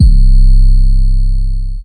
Gamer World 808 1.wav